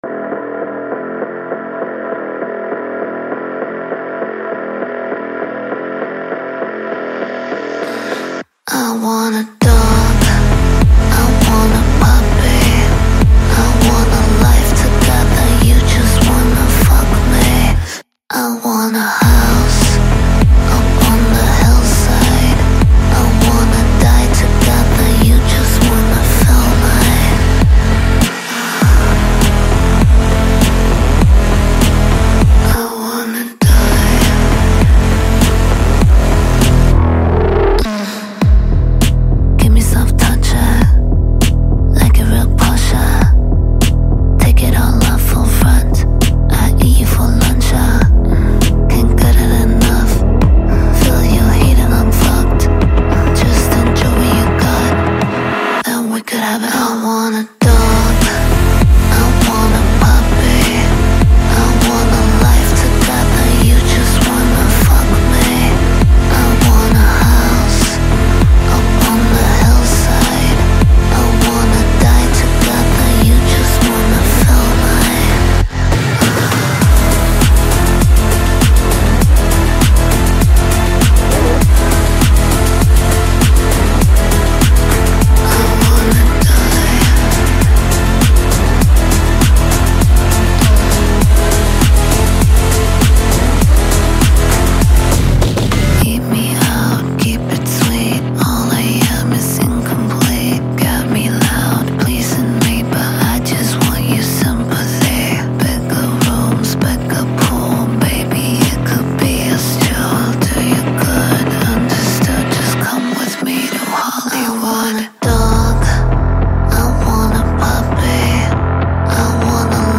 smooth vocals
rich production